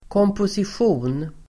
Uttal: [kåmposisj'o:n]